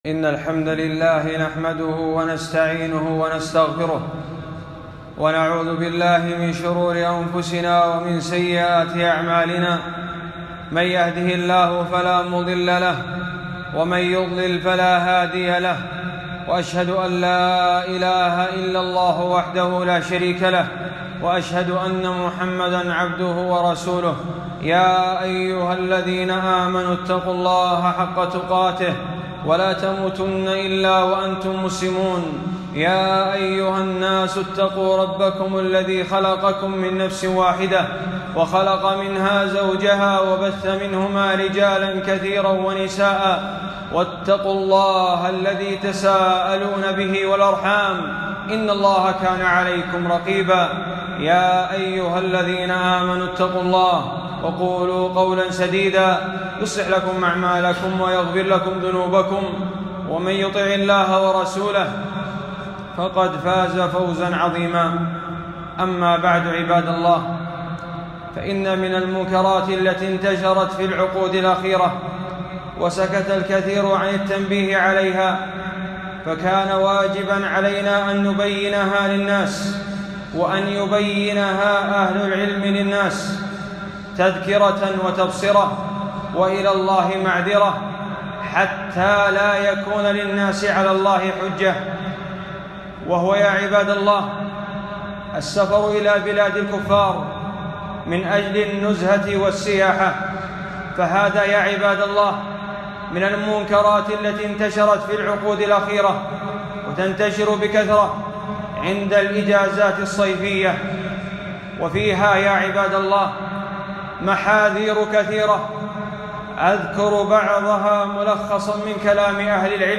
خطبة - مفاسد السفر إلى بلاد الكفار والبلاد التي تنشر فيها المنكرات